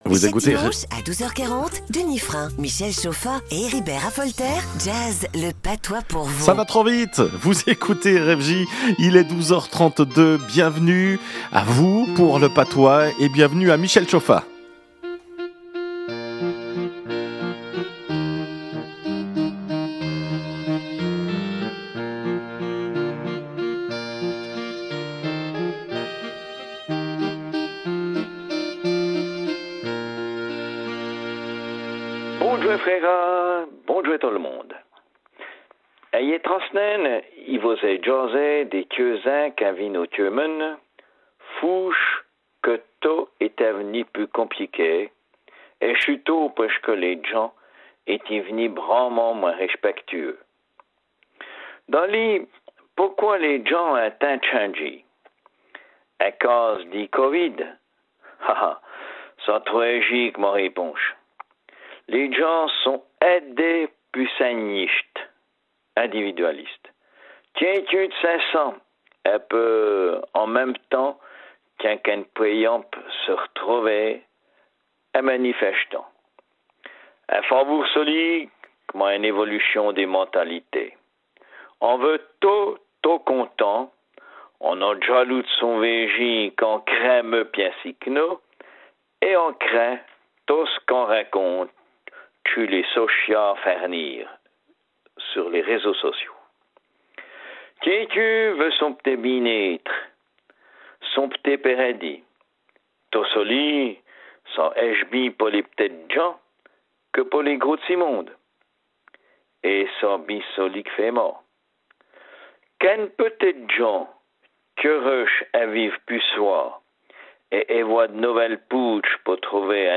Radio Fréquence Jura RFJ rubrique en patois, Thème : Les gens changent ... folie du moment et annonce du prochain spectacle à Charmoille